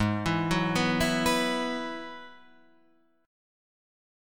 G#m7#5 Chord